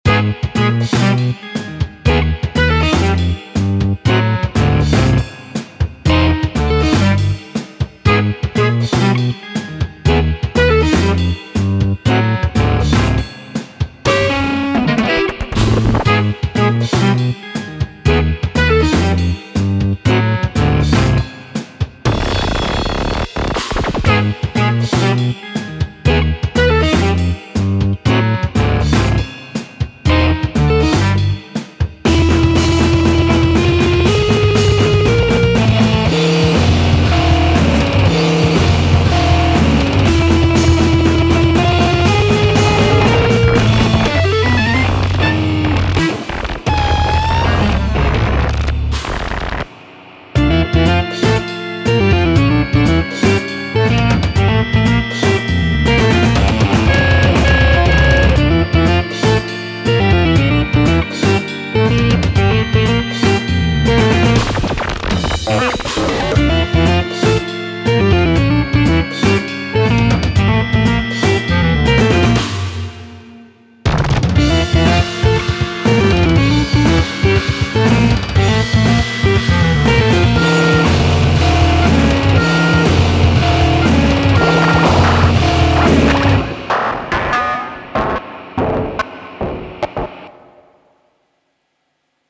0-Coast / Kontakt / Vstis / Tape Mello Fi